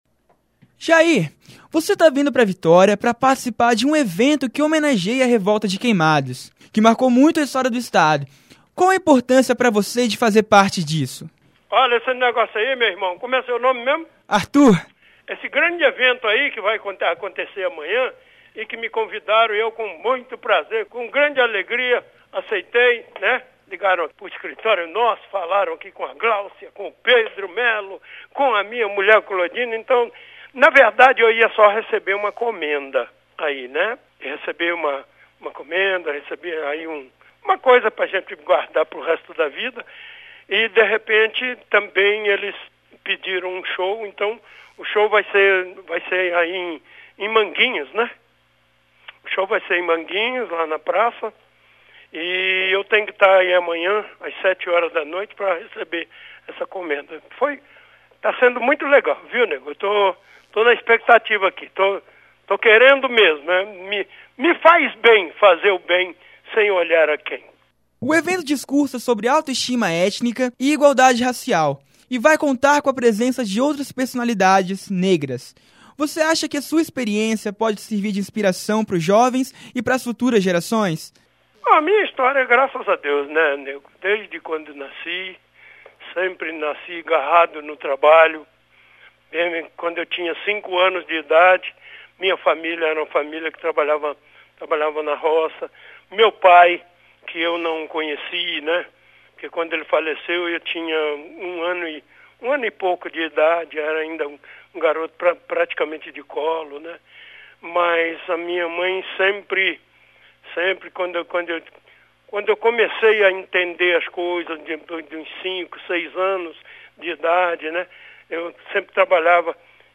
Entrevista com Jair Rodrigues Download : Entrevista com Jair Rodrigues